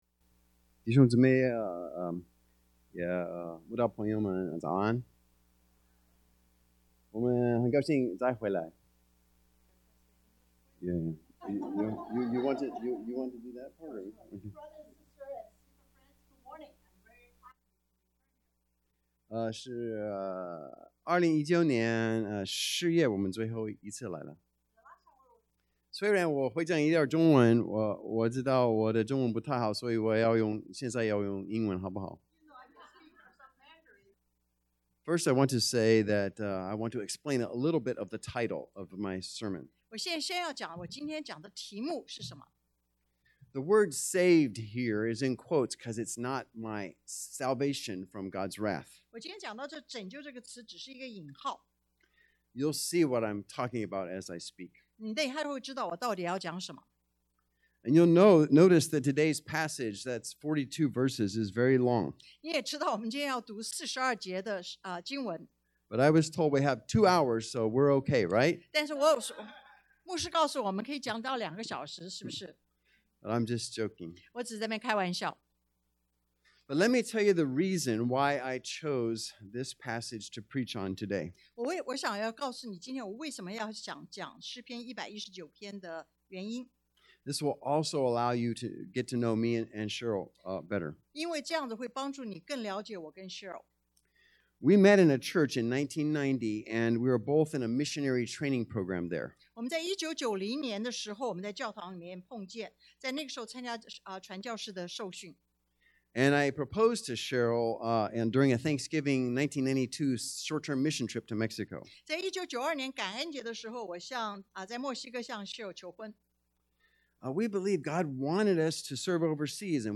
詩Psalm 119:1-42 Service Type: Sunday AM 1.